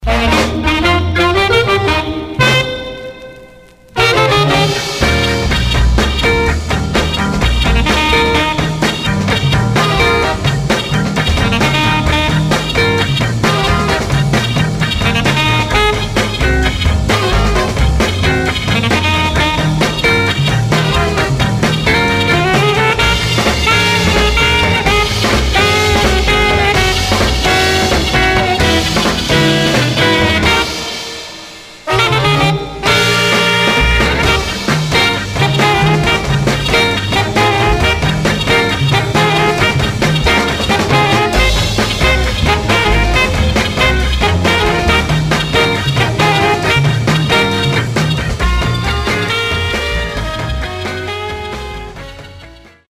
Some surface noise/wear Stereo/mono Mono
Funk